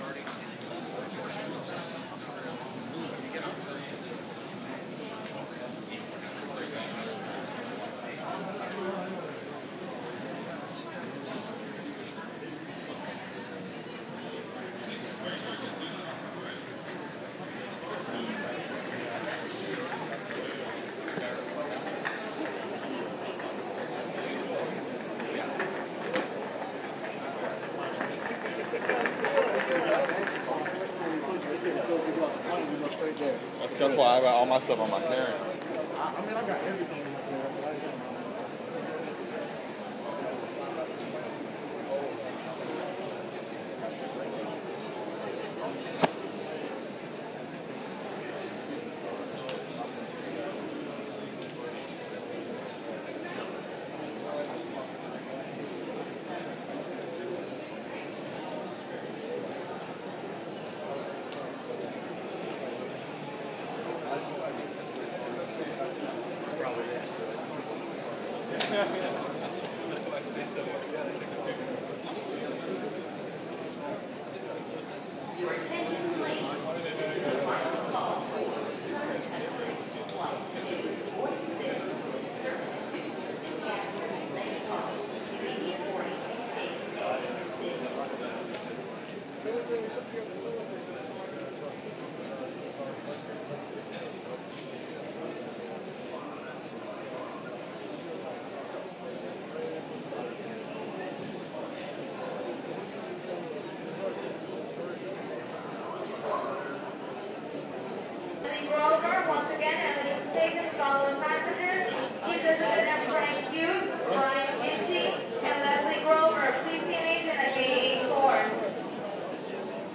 terminal 4 Jfk airport